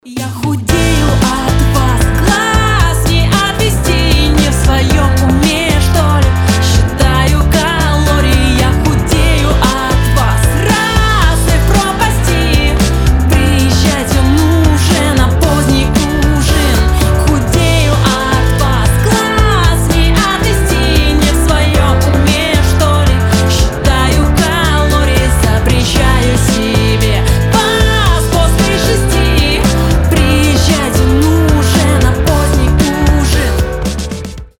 забавные
indie pop
Инди-поп